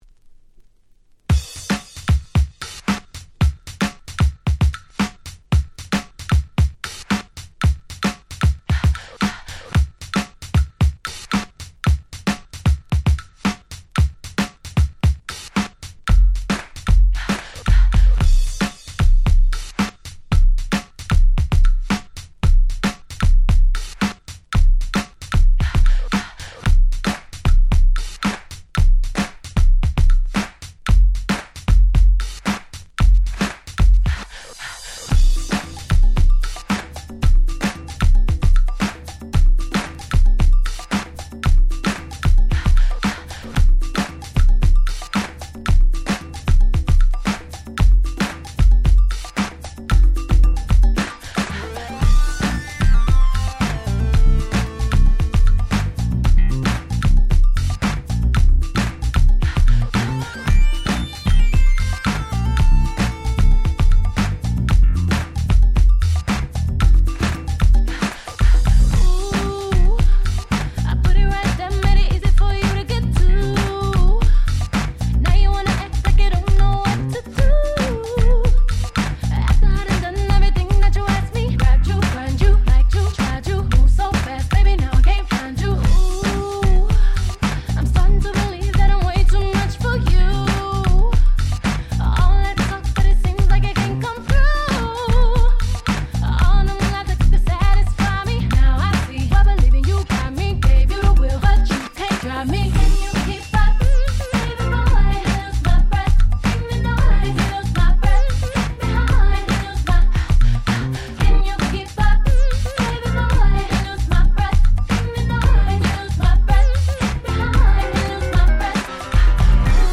07' Nice Reggae Remix / Re-Edit !!